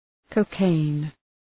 cocaine.mp3